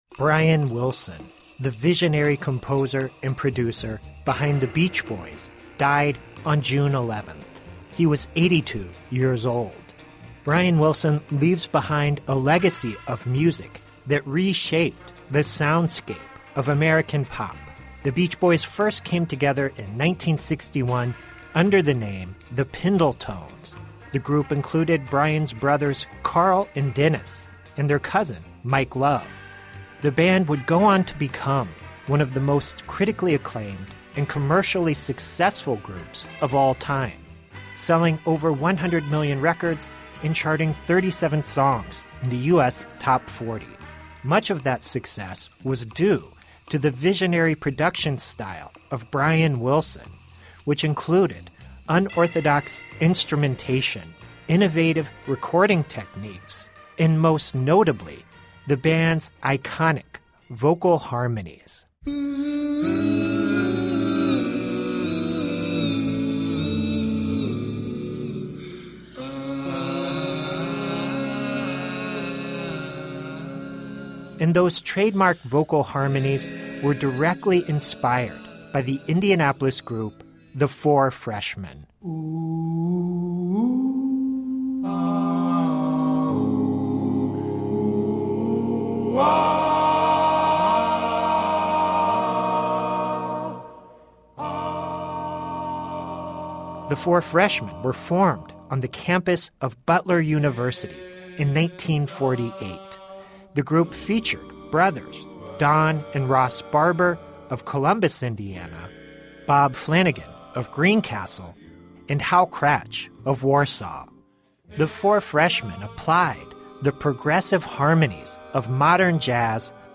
Interview: The late Beach Boys' founder Brian Wilson on Indianapolis' The Four Freshmen